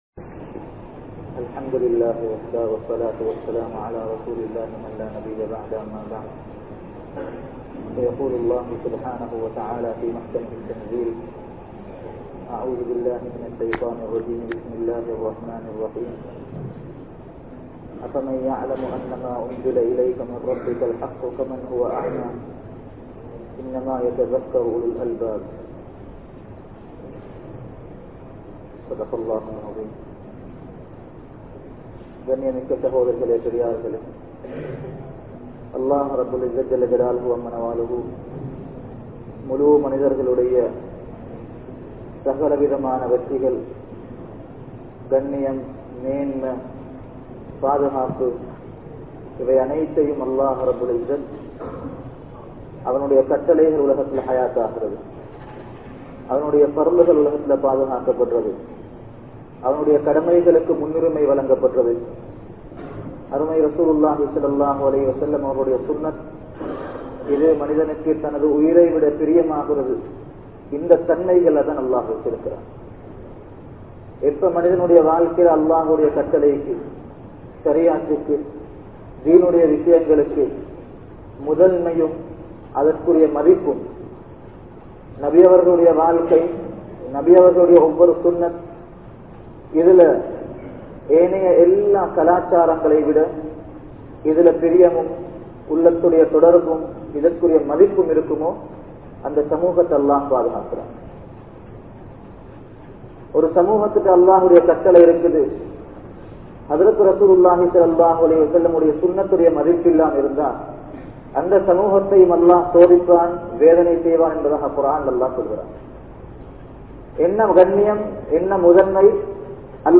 Allah`vukkaaha Vaalungal (அல்லாஹ்வுக்காக வாழுங்கள்) | Audio Bayans | All Ceylon Muslim Youth Community | Addalaichenai